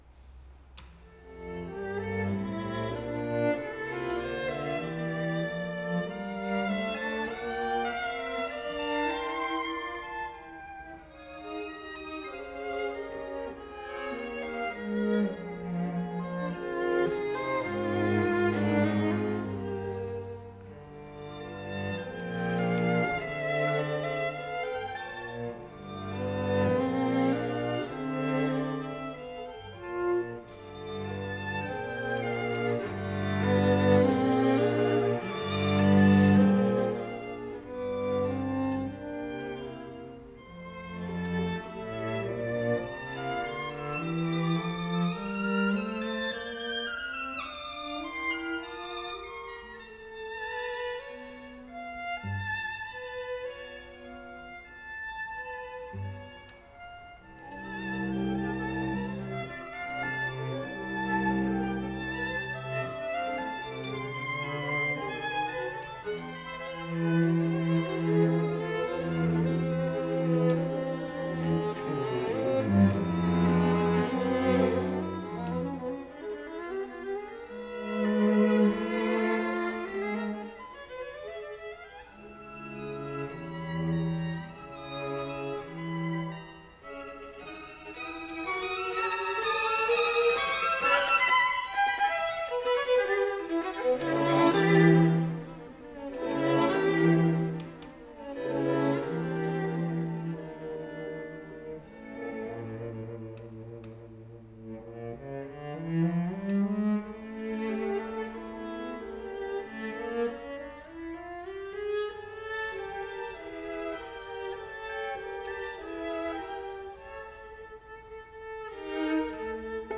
the students
Chamber Groups
Allegro moderato